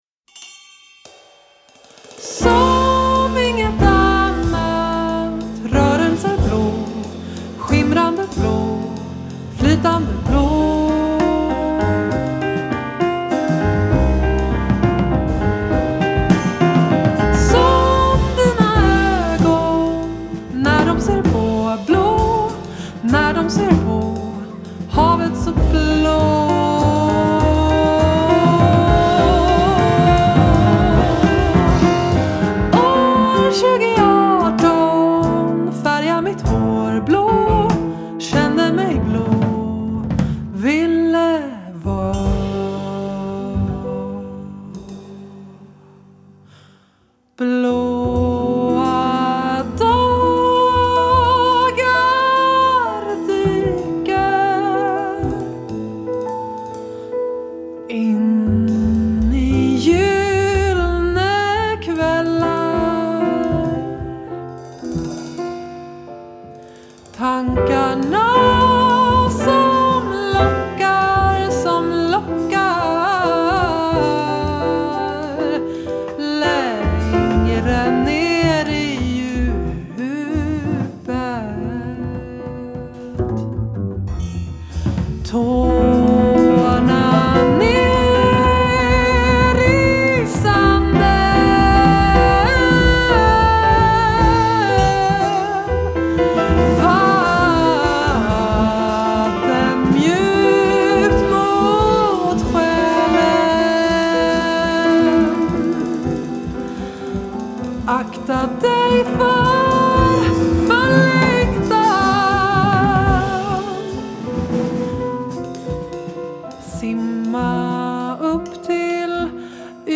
piano
saxophone